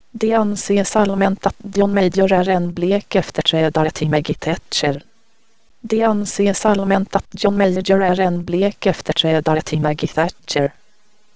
Speech synthesis example.